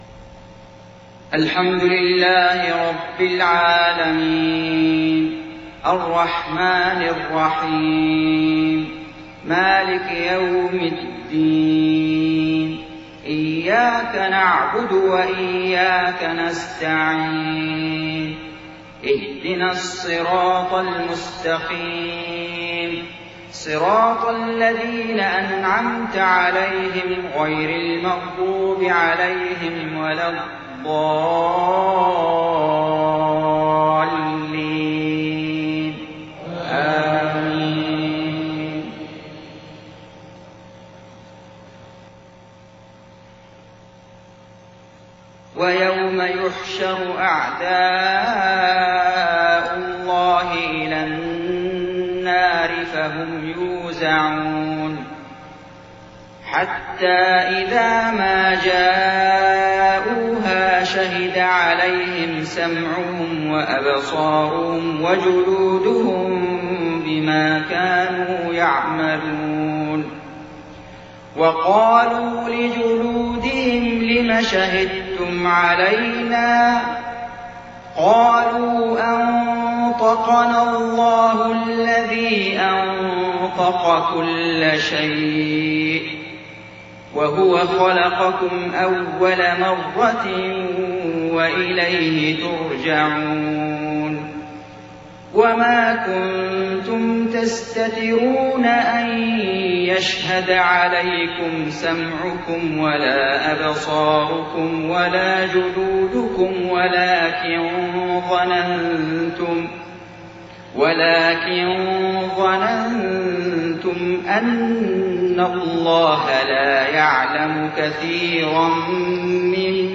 صلاة العشاء 27 محرم 1430هـ من سورة فصلت 19-29 > 1430 🕋 > الفروض - تلاوات الحرمين